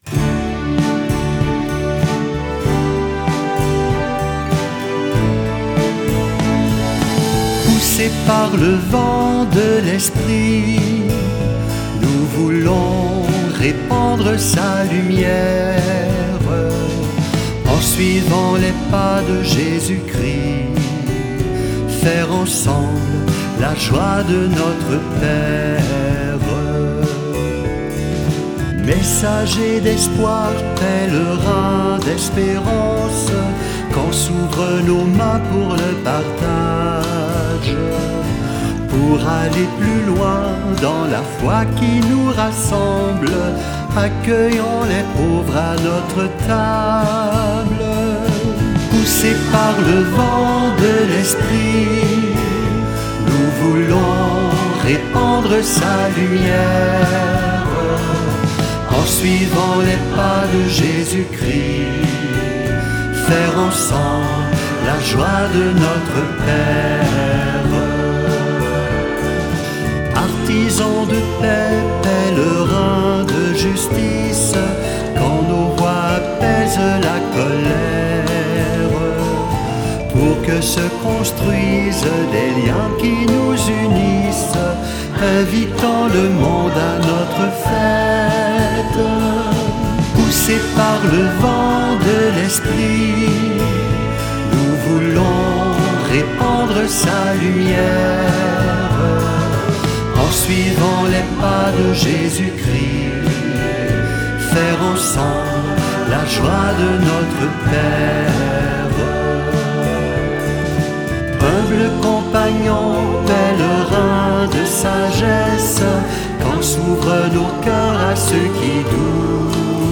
Chant thème de l'année 2025-2026